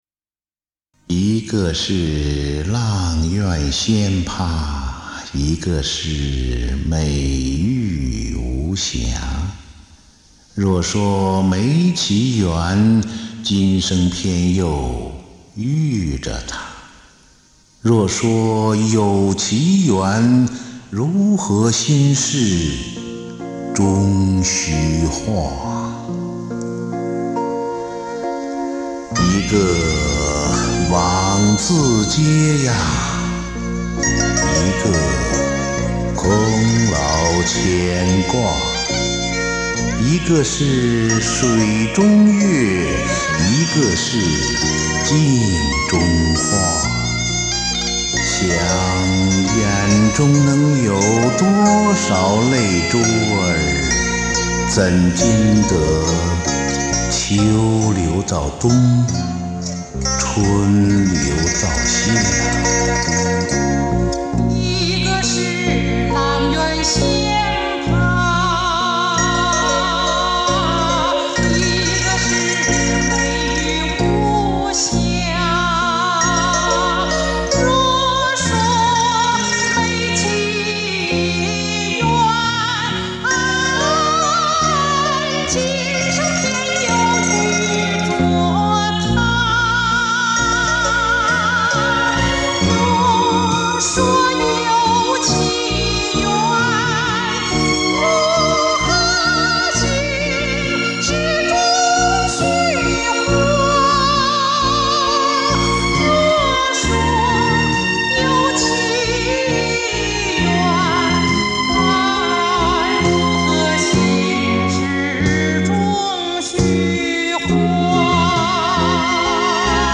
童声合唱